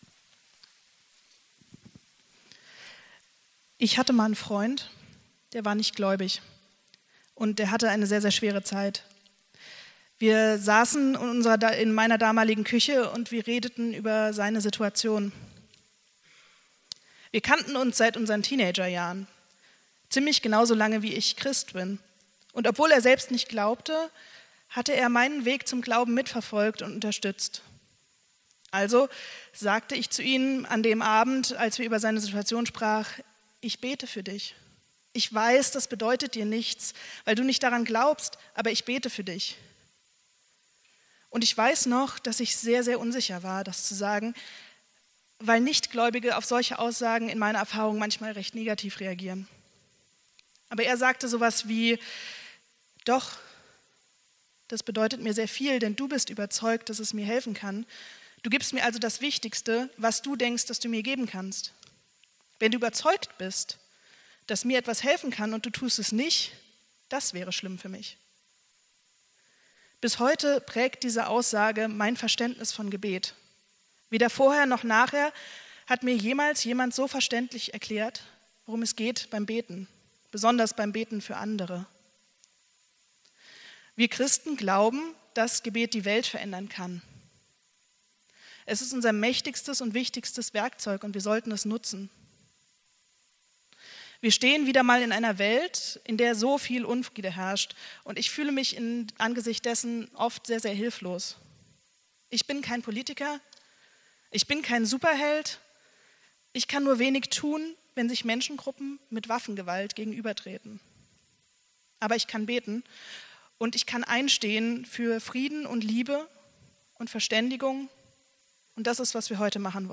Predigt vom 21.04.2024